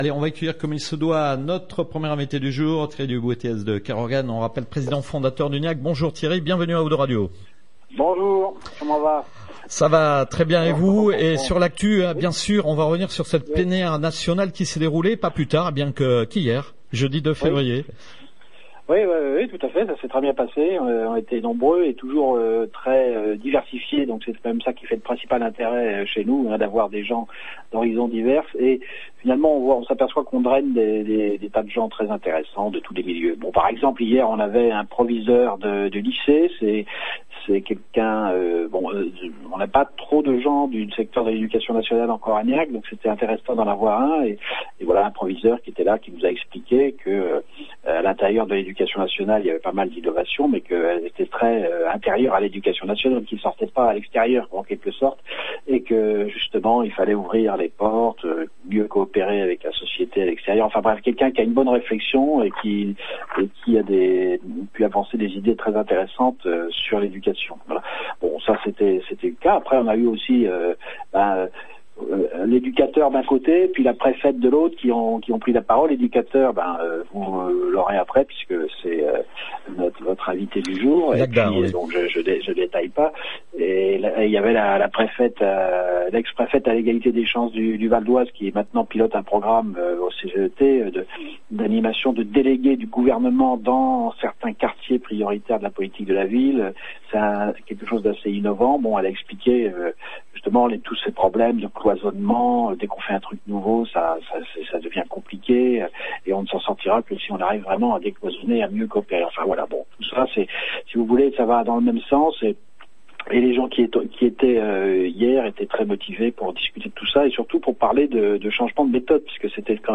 08/02/2017 : Nouvelle interview GNIAC / 02 Radio : Retours sur la plénière du 2 février et focus sur Médiation Nomade